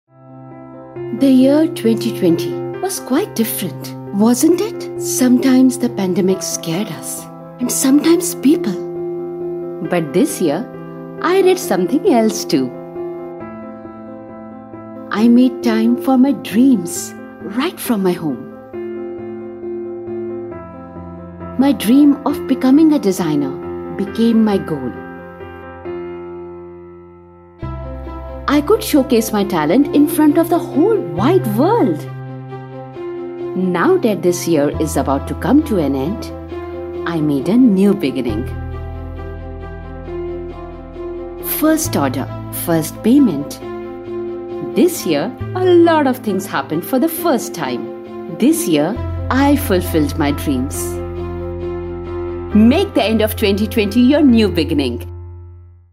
Inglés (indio)
Vídeos explicativos
Equipo de estudio para el hogar
Micrófono: Shure SM58